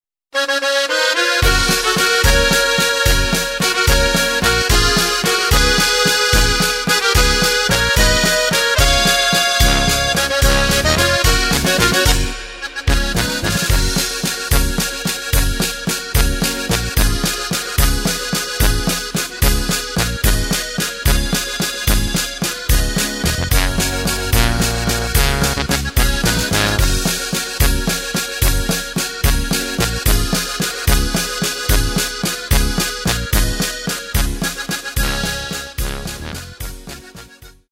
Takt:          3/4
Tempo:         220.00
Tonart:            F#
Walzer aus dem Jahr 2010!
Playback mp3 Demo